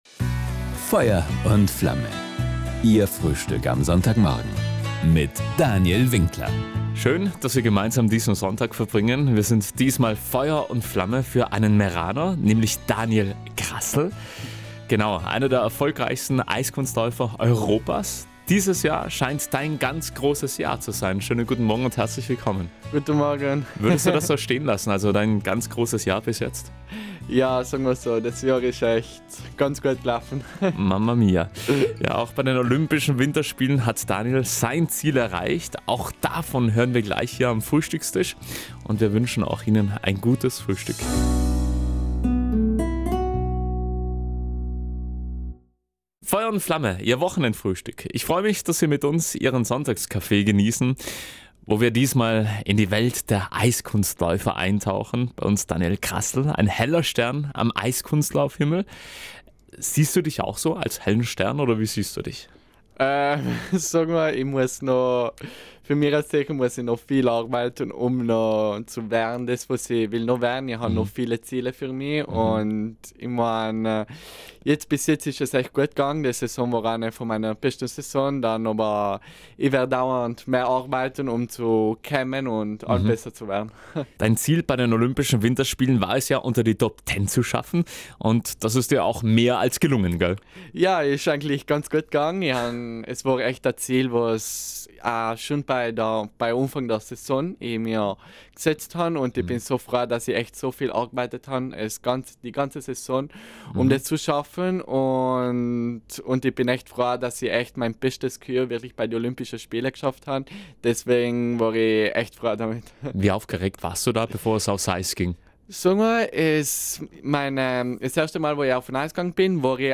Diesmal ist der sympathische Meraner Gast im Sonntagsfrühstück auf Südtirol 1.